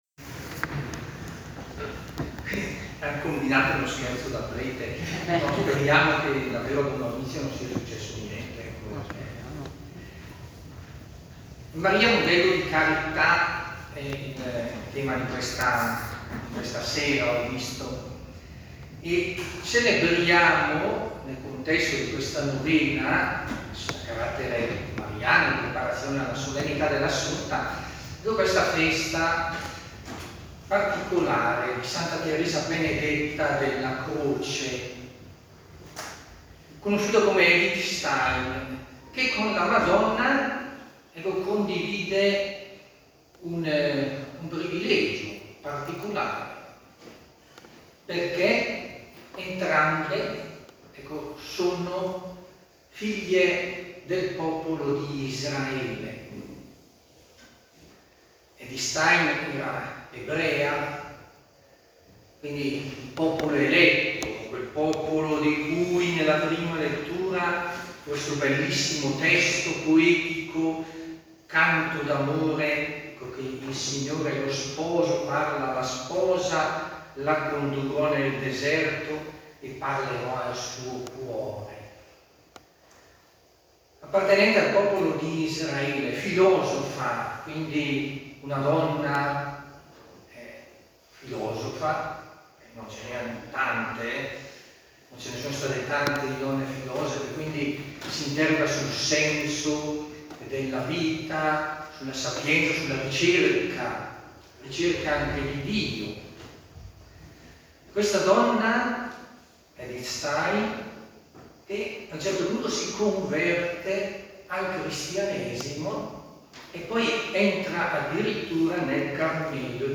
Omelia-9-agosto-2022.mp3